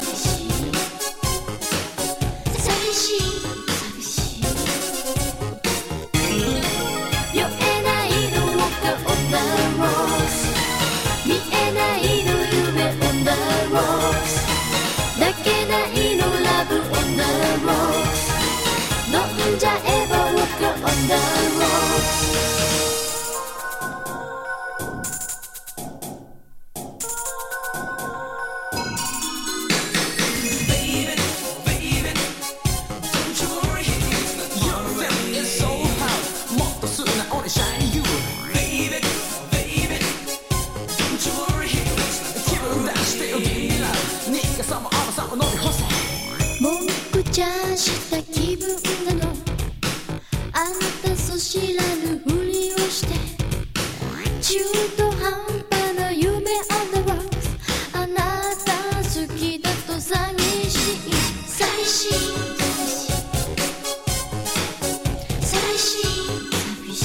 エスノ・エレクトロ・テクノ・ラップ歌謡